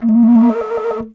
Index of /90_sSampleCDs/Best Service ProSamples vol.52 - World Instruments 2 [AIFF, EXS24, HALion, WAV] 1CD/PS-52 AIFF WORLD INSTR 2/WOODWIND AND BRASS/PS MOCENO BASSFLUTE LICKS